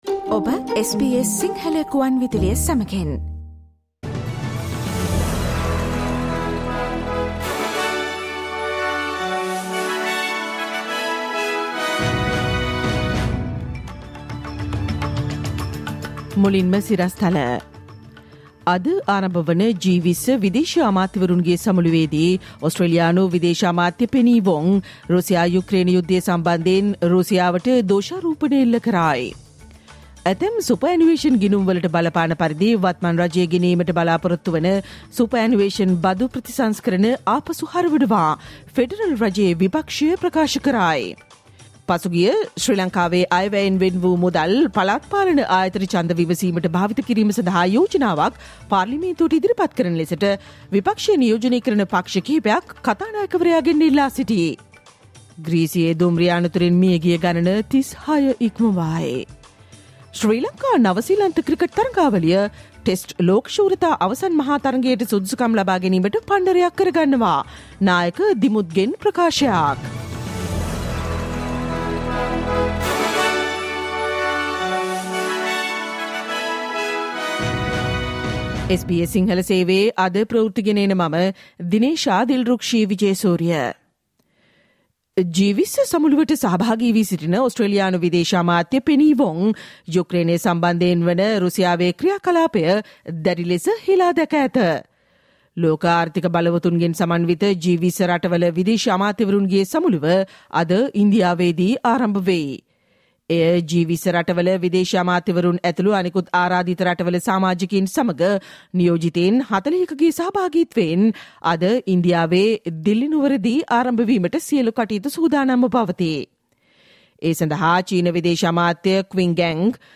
Listen to the latest news from Australia, Sri Lanka, and across the globe, and the latest news from the sports world on the SBS Sinhala radio news bulletin on Monday, Tuesday, Thursday, and Friday at 11 am.